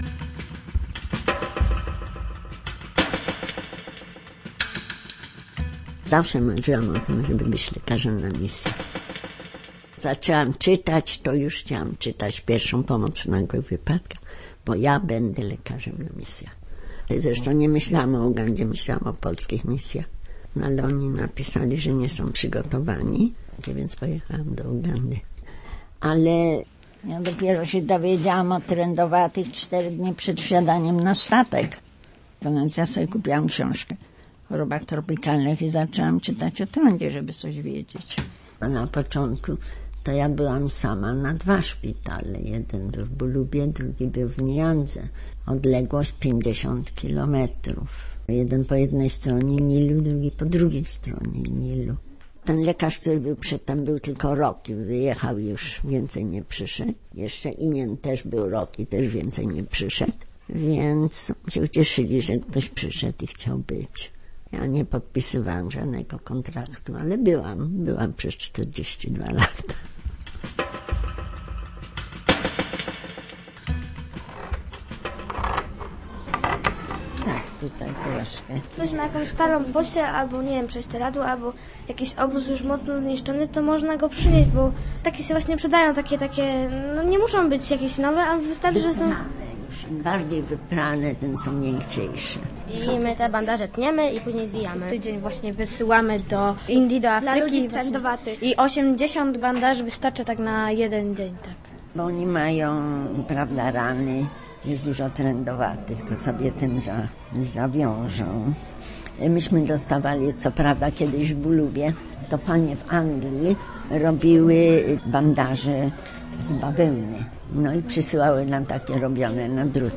Bez kontraktu - reportaż